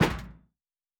Metal Box Impact 1_1.wav